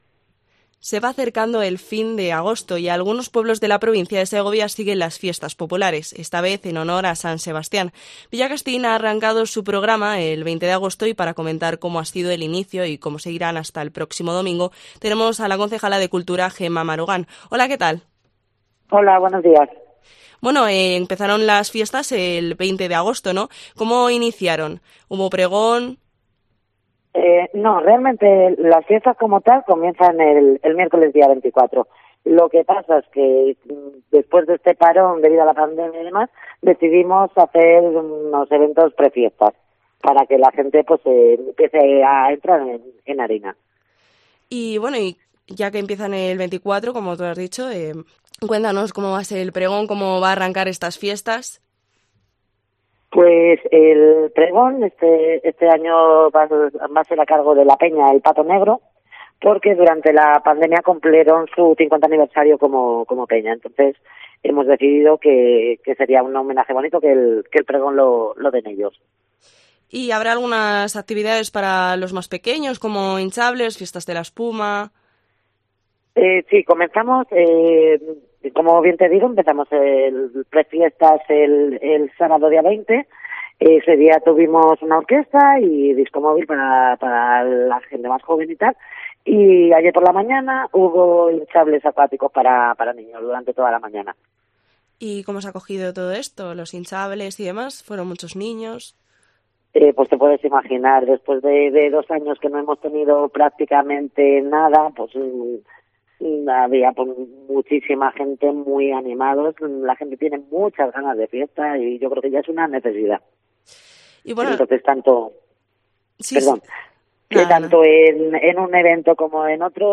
Gema Marugán, concejala de Cultura de Villacastín, habla de las fiestas 2022